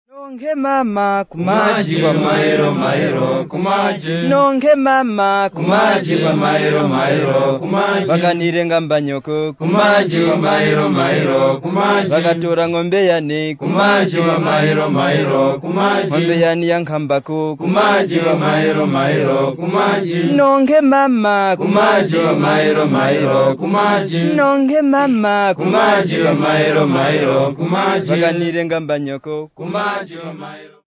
Boys at Dedza Secondary School
Folk music--Africa
Folk songs, Tonga (Nyasa)
Field recordings
Africa Malawi Mzimba f-mw
sound recording-musical